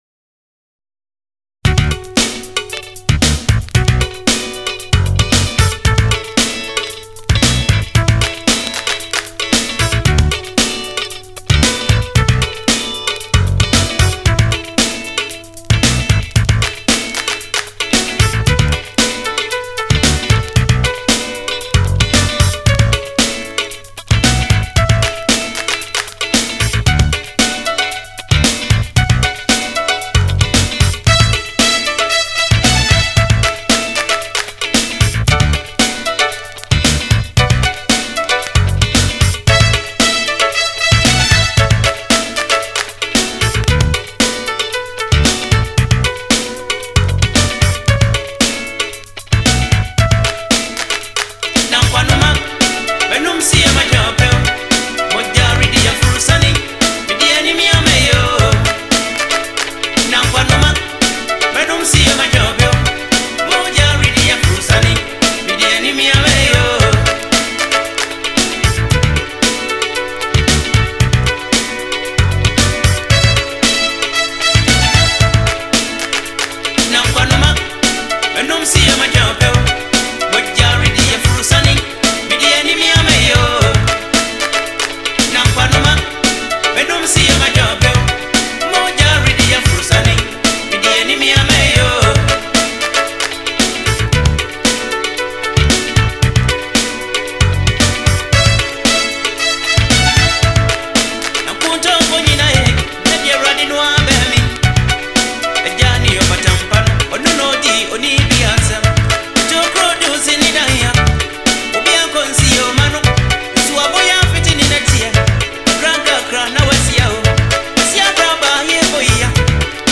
highlife
emotional delivery
pure Ghanaian highlife rhythms